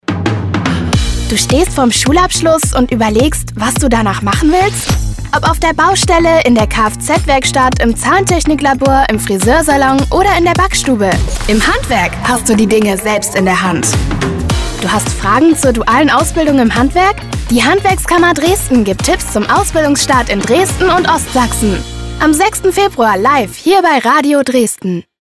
In die Arbeit vorm Mikrofon ist sie quasi hineingewachsen und wird auch heute noch mitunter als aufgeweckte junge "Kinderstimme" in der Werbung oder auch für Hörspiele oder Synchron gebucht.
Funk 2025